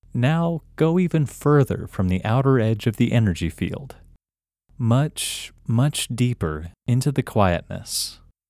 OUT – English Male 9